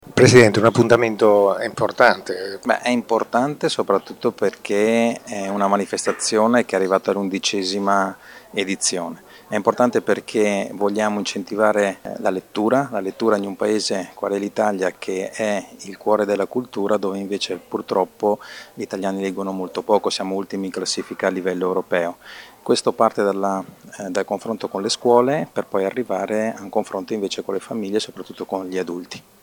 ha intervistato alcuni dei presenti alla conferenza stampa: